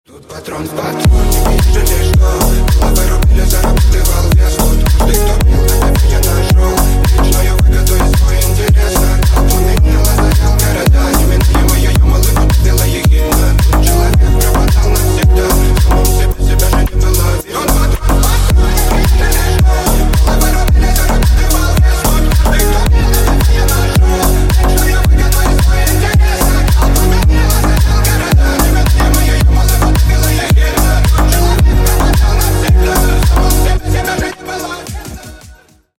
Рингтоны Ремиксы » # Рэп Хип-Хоп